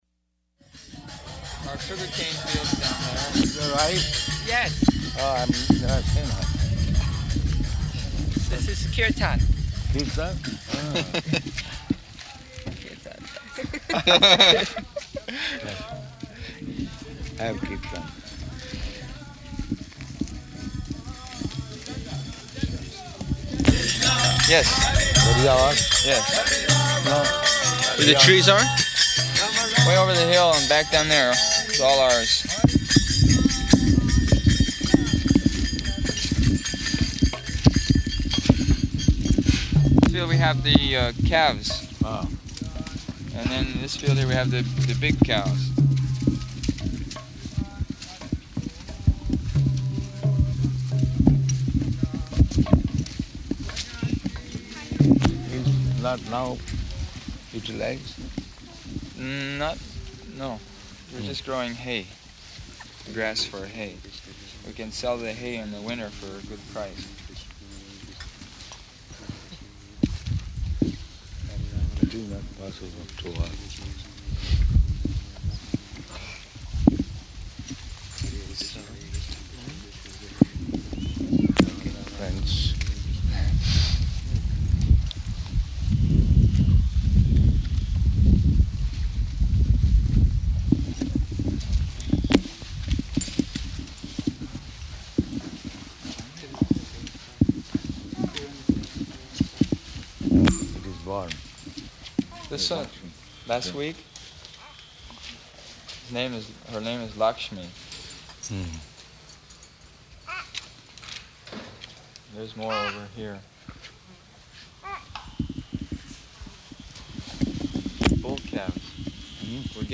-- Type: Walk Dated: August 1st 1975 Location: New Orleans Audio file
[ kīrtana in background]